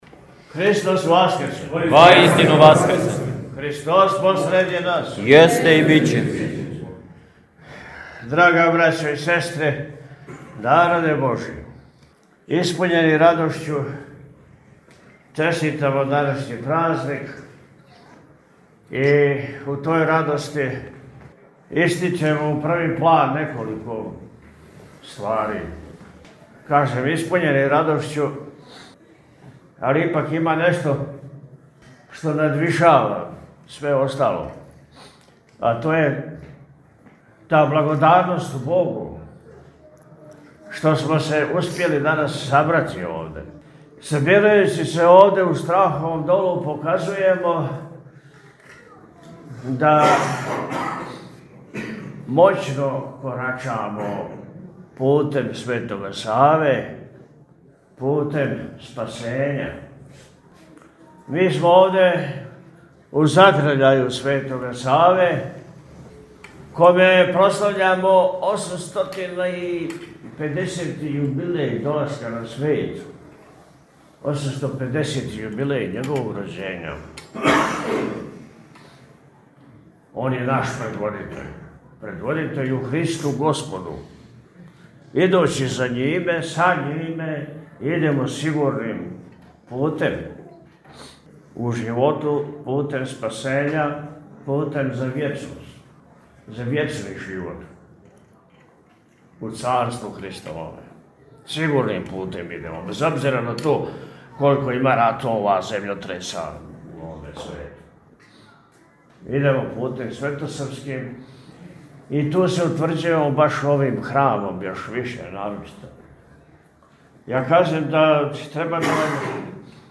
По завршеној вечерњој служби Високопреосвећени се сабранима обратио пригодном пастирском беседом у којој је, поред осталог, рекао: – Сабирајући се овде, у Страховом Долу, показујемо да моћно корачамо путем Светога Саве, путем спасења.